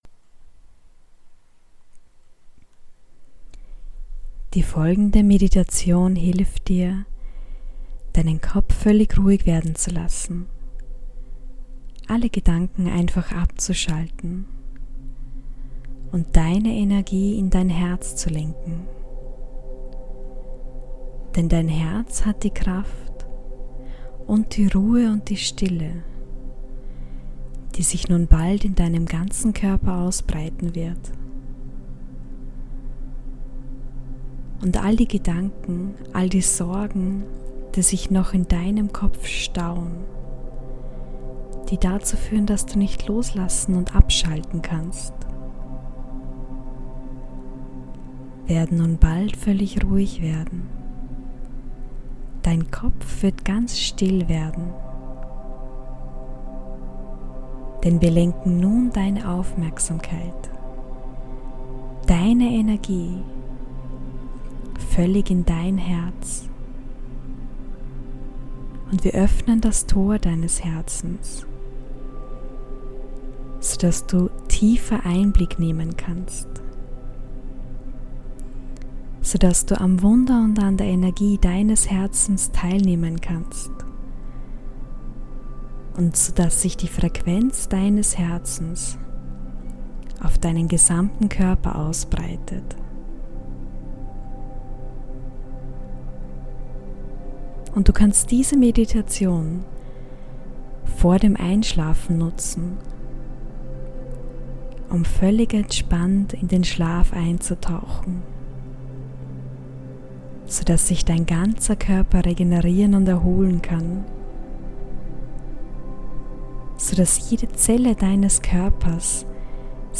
sleep+meditation+hörprobe.mp3